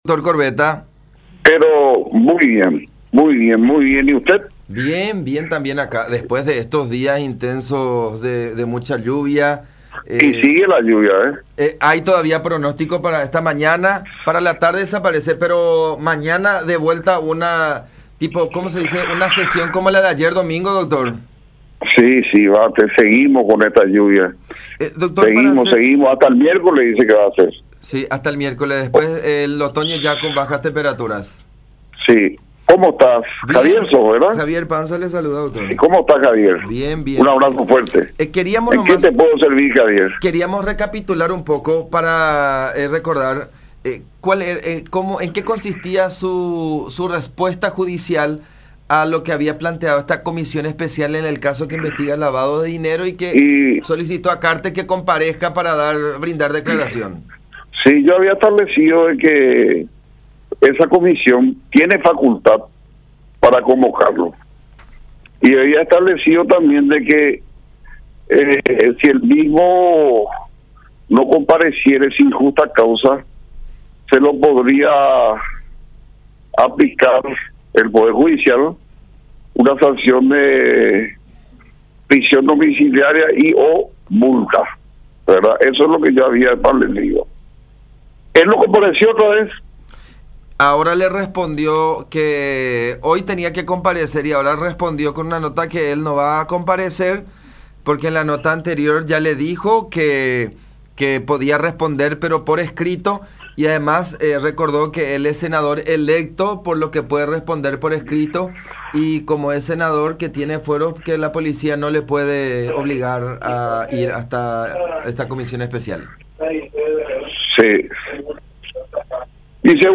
12-JUEZ-ALCIDES-CORBETA.mp3